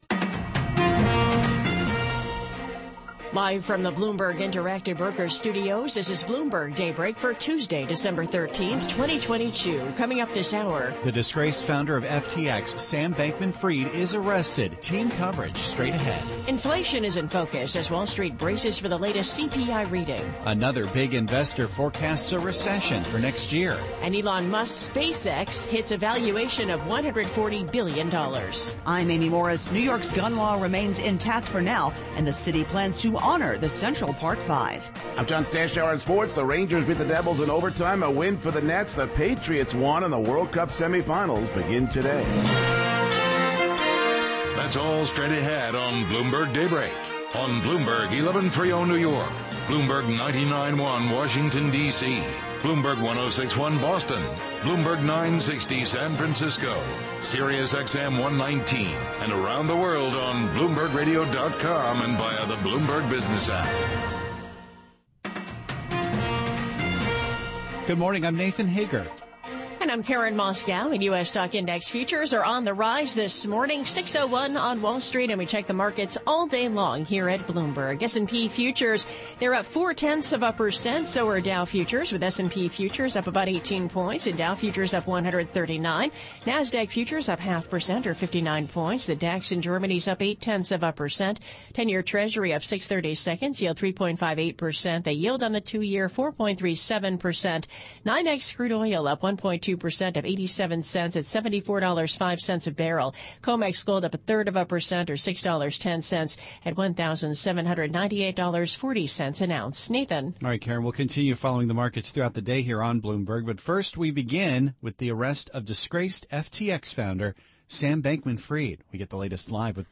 Bloomberg Daybreak: December 13, 2022 - Hour 2 (Radio)